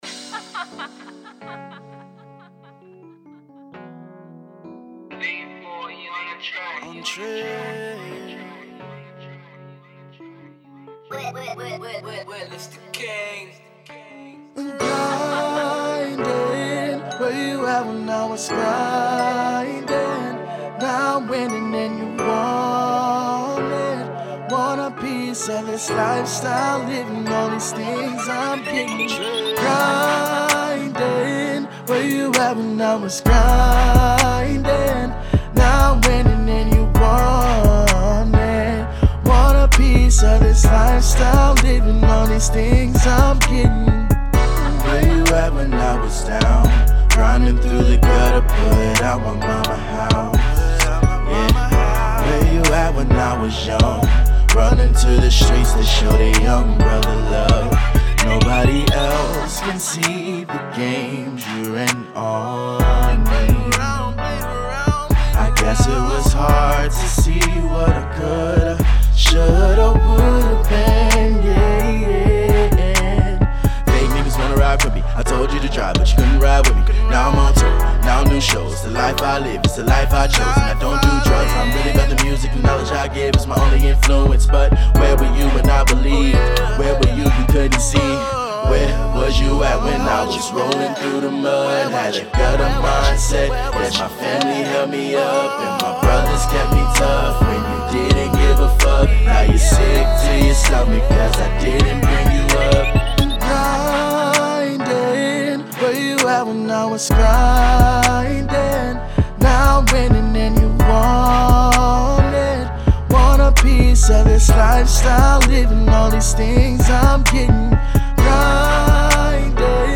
RnB